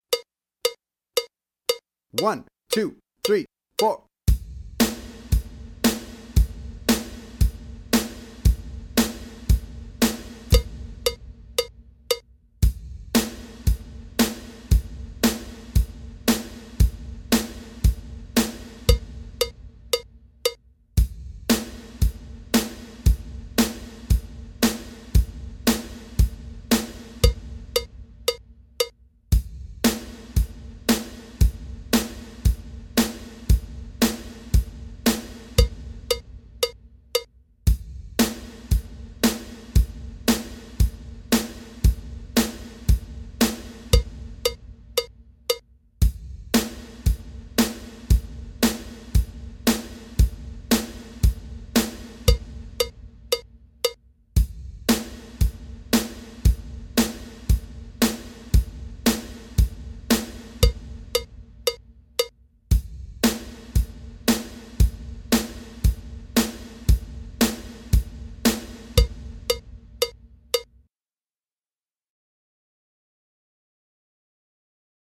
Drum Fill Practice Loop